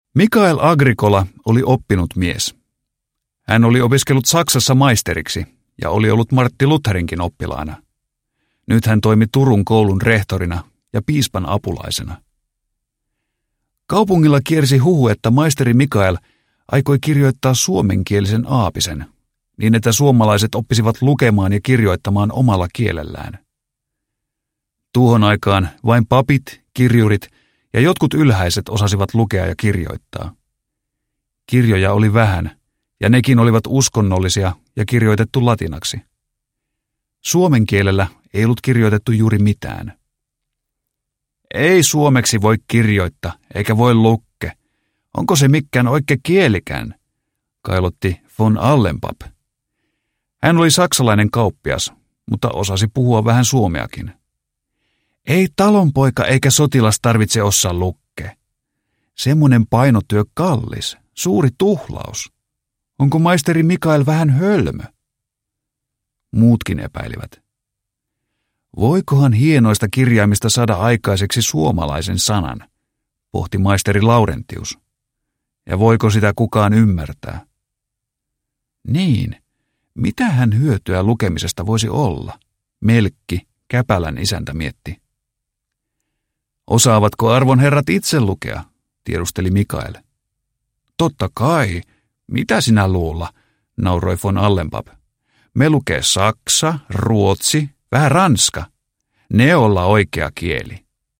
Koiramäen Suomen historia – Ljudbok